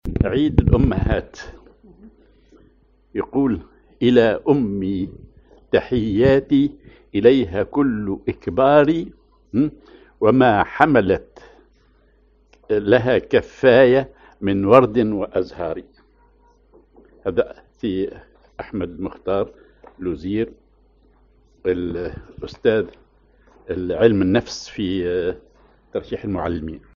Maqam ar اصبعين
genre نشيد